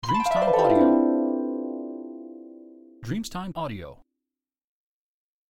Scintillio di caduta del carillon dell'arpa
• SFX